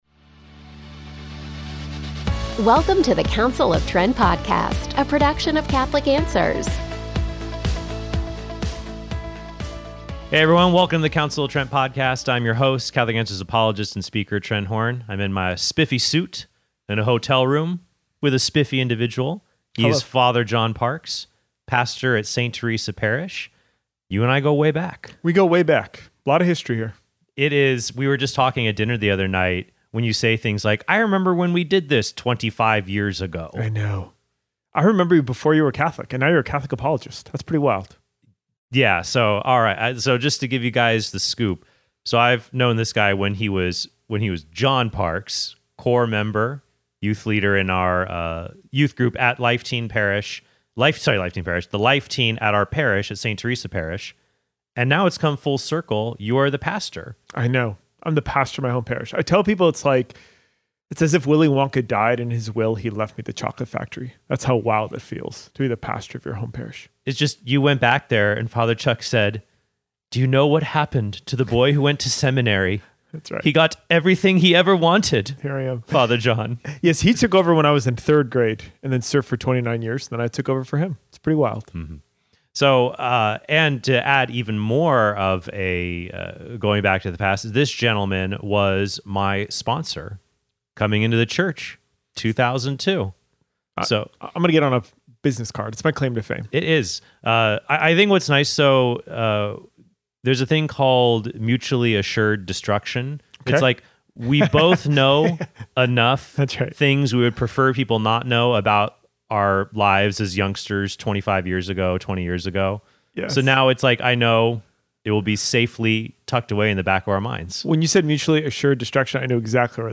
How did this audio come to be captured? at Franciscan University of Steubenville’s defending the faith conference